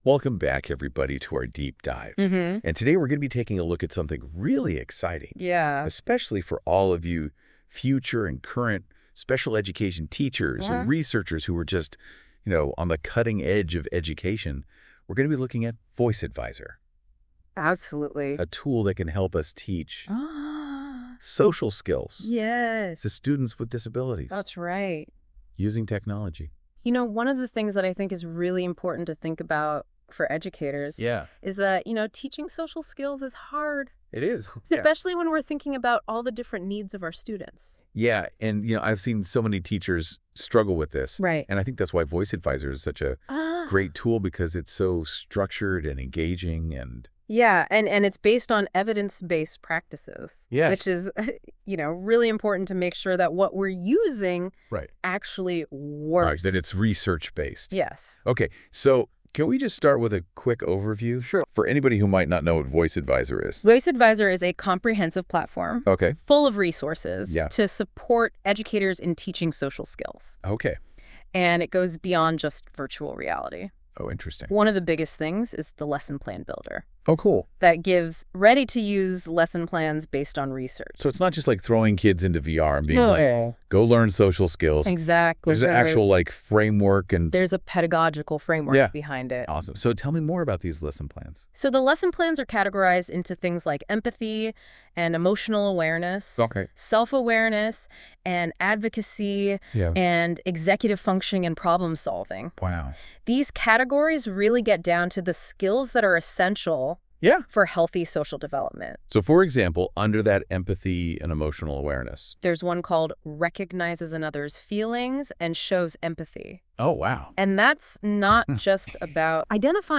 Listen to a discussion about this blog
by VOISS / iKnow | Created Using NotebookLM